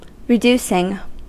Ääntäminen
Ääntäminen US Haettu sana löytyi näillä lähdekielillä: englanti Käännös Konteksti Adjektiivit 1. reduzierend 2. reduktiv kemia Reducing on sanan reduce partisiipin preesens.